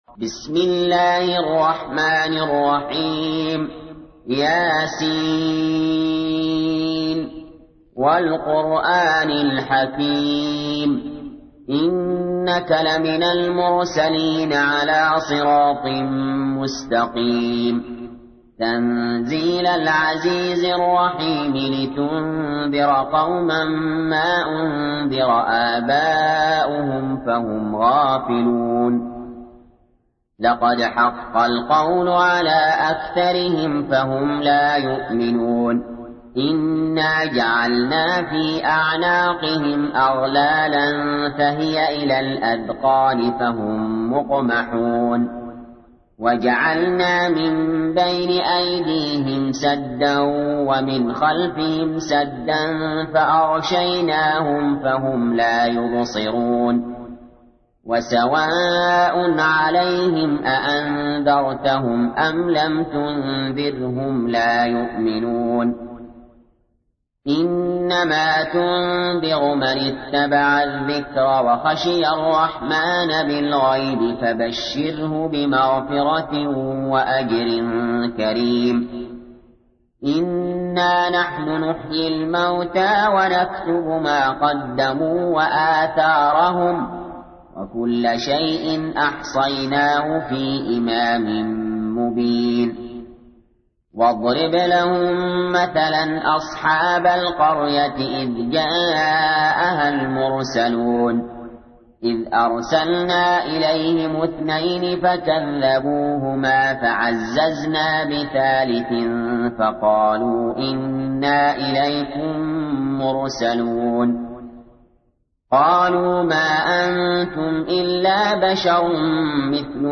تحميل : 36. سورة يس / القارئ علي جابر / القرآن الكريم / موقع يا حسين